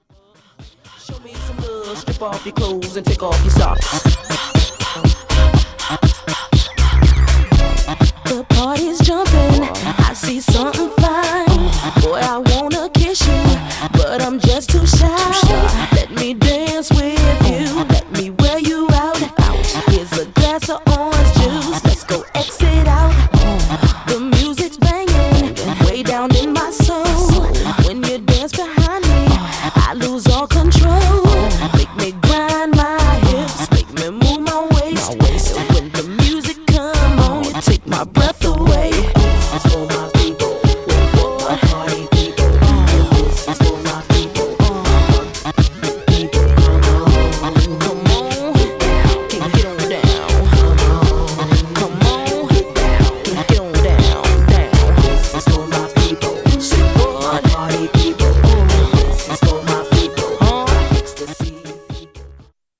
Styl: Hip Hop, House